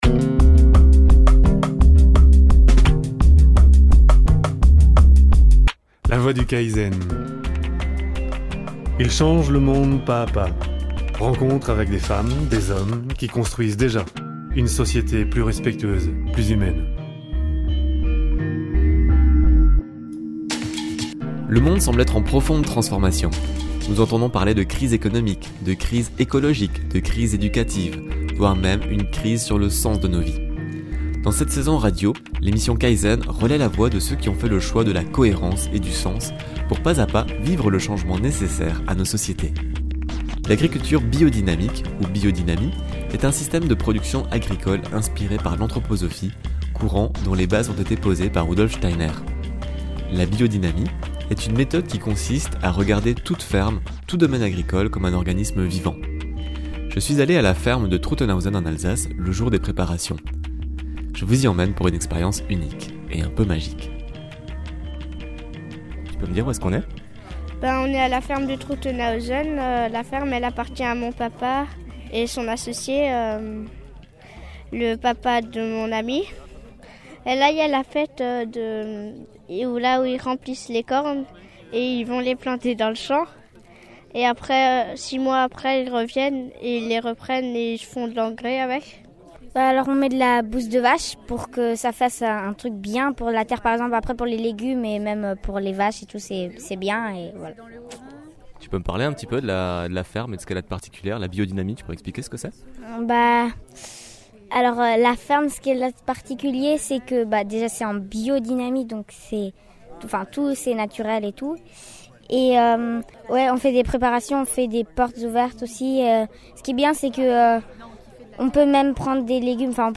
Biodynamie 1_internet - Documentaires Sonores